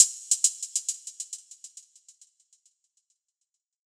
Closed Hats